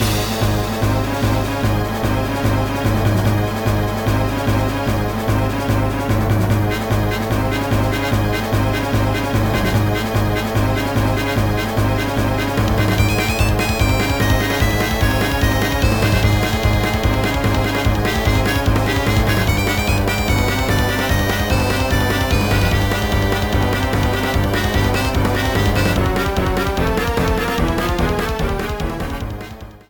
The battle theme for Bowser and Shrowser